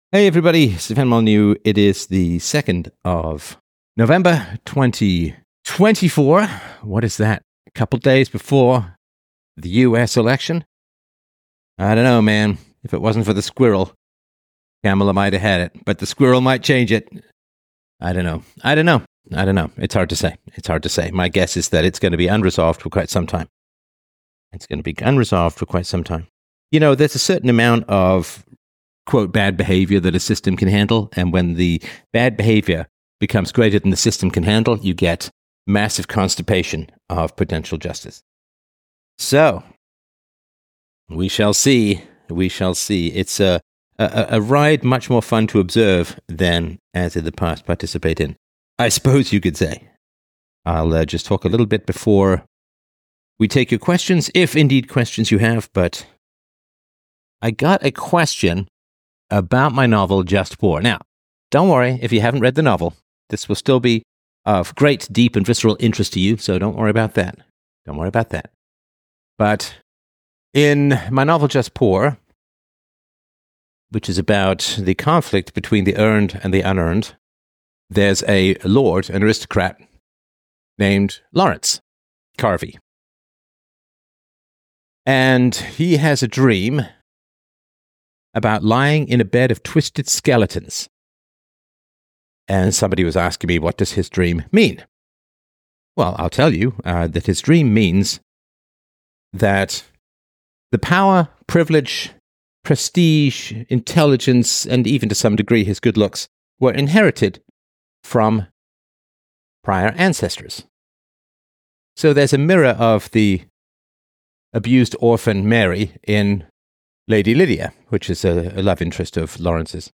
1 5724 Escaping Guilt and Shame! Flash Freedomain Livestream 1:05:08 Play Pause 1h ago 1:05:08 Play Pause Afspil senere Afspil senere Lister Like Liked 1:05:08 Flash Livestream 2 November 2024 In this episode, I examine the interplay between privilege and identity, particularly in the context of the upcoming US elections. Using my novel "Just Poor" as a backdrop, I discuss the burdens of inherited privilege through the experiences of characters Lawrence and Mary.